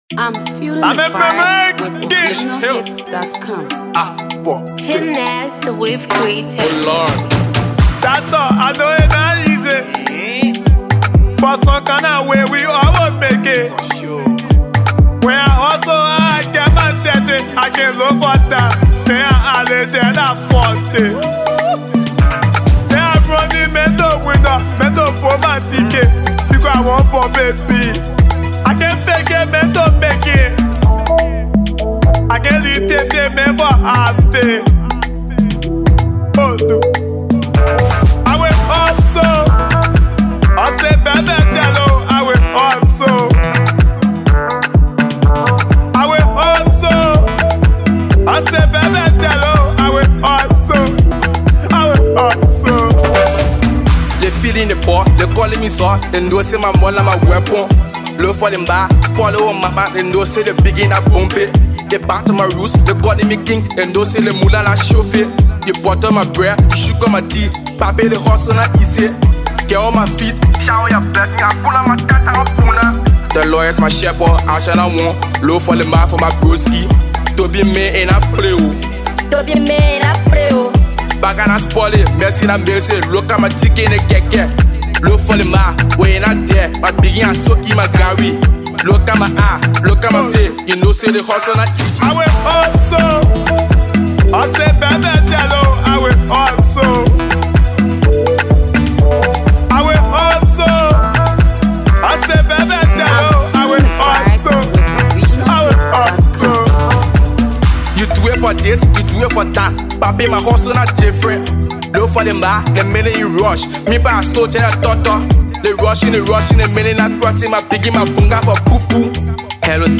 trapco banger